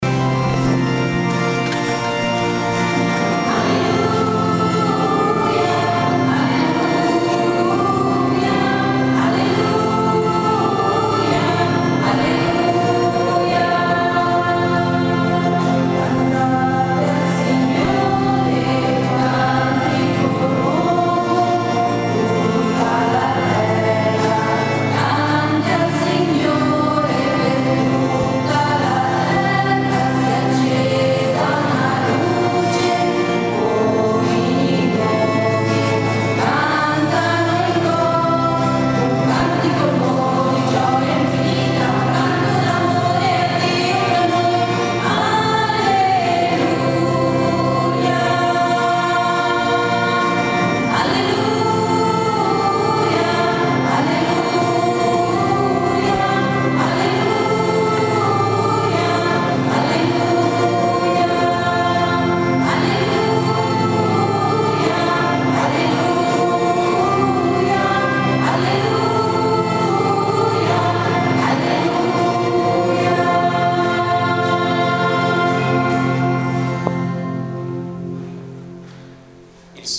Alleluia